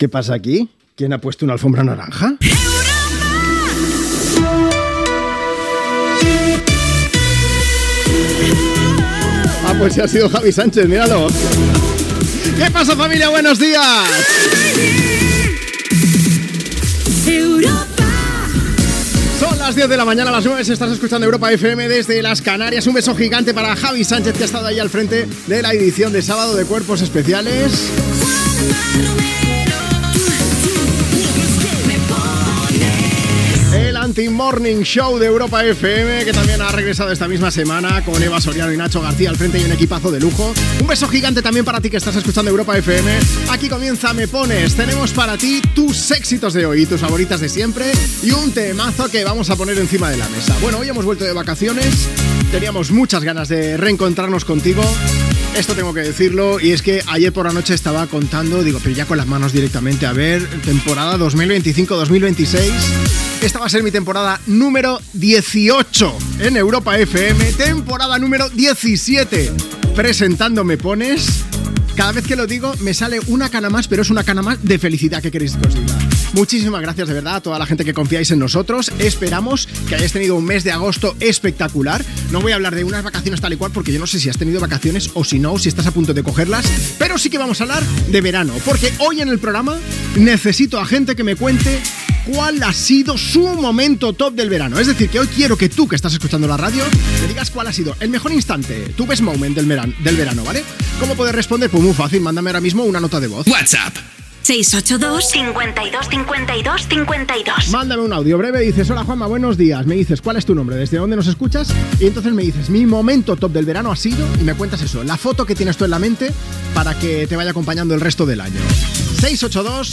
Indicatiu de la ràdio, careta, hora, el retorn de vacances.
Entreteniment